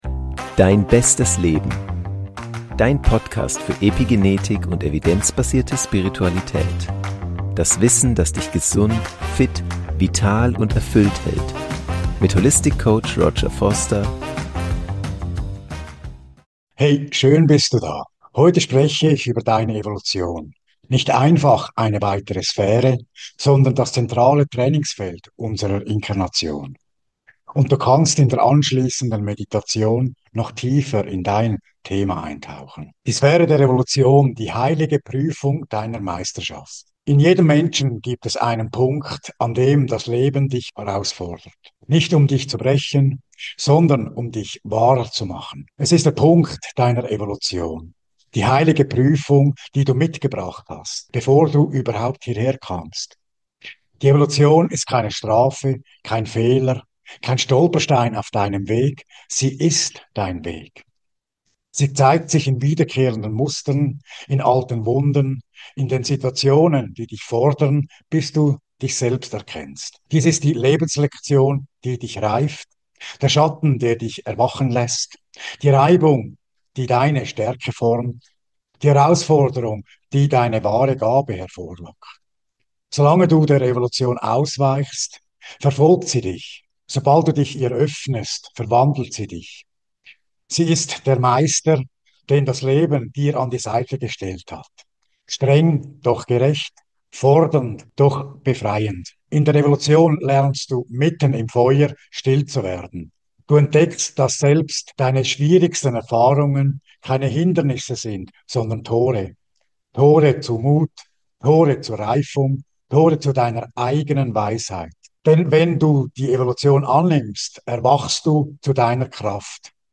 Die Prüfung, der Du nicht entkommst | Aus der Meisterschaft entsteht | Meditation zur Evolution ~ Dein bestes Leben: Evidenzbasierte Spiritualität und Epigenetik Podcast